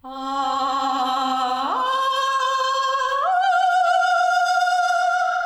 vocals.wav